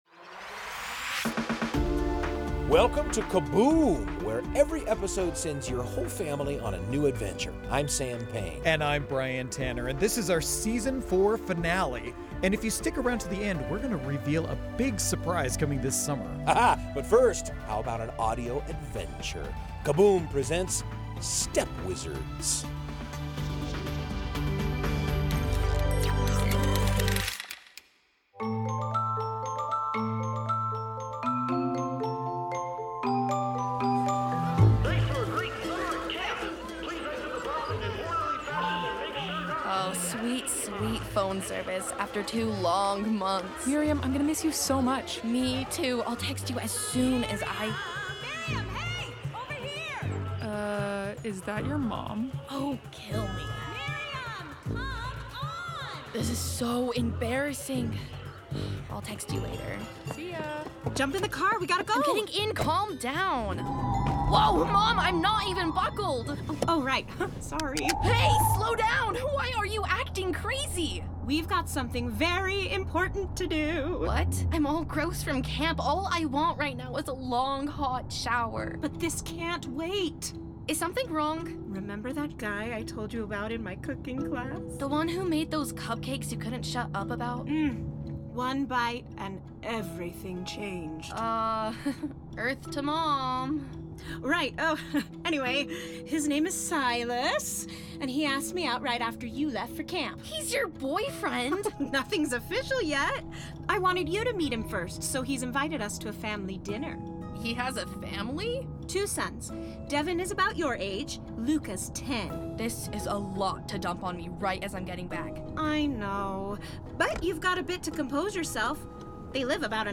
The whole family will love listening to these original fiction stories together, which feature full casts of talented voice actors and cinematic sound design. Each episode is its own complete story, so jump in anywhere you like.